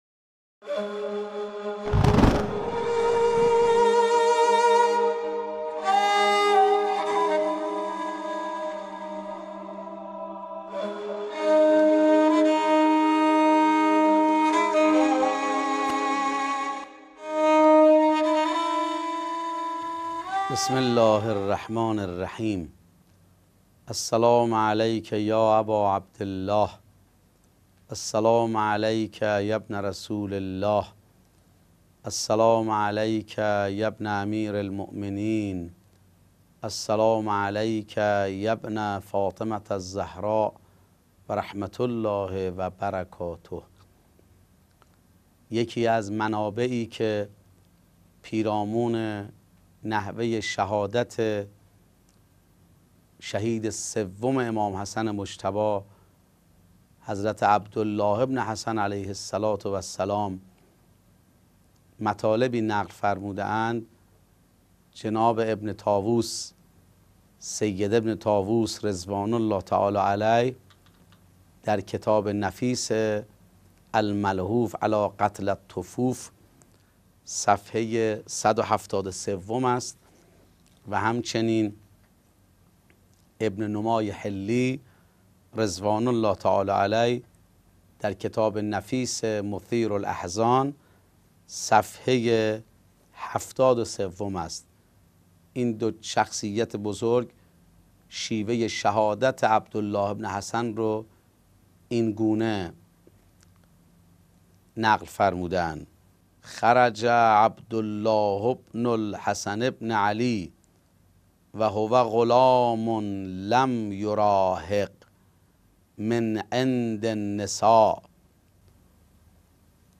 سلسله گفتارهایی پیرامون تبارشناسی عاشورا